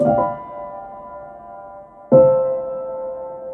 failsound.wav